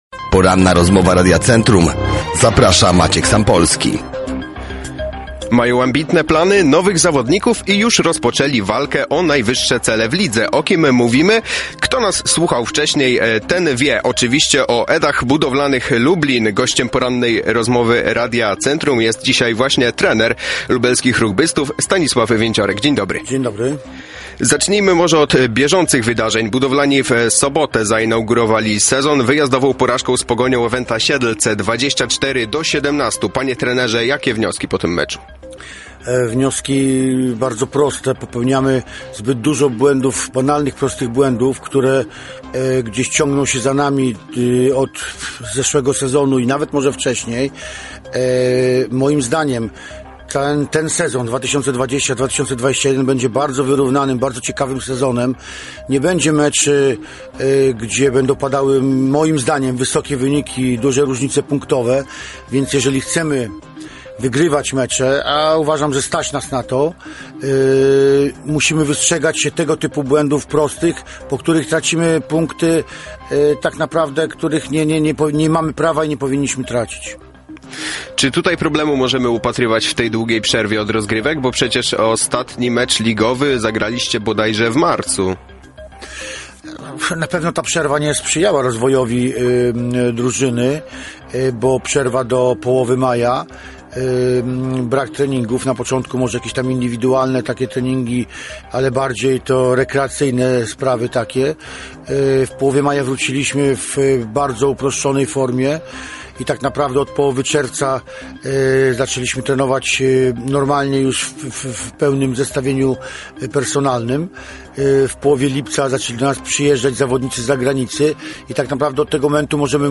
Całą rozmowę znajdziecie tutaj: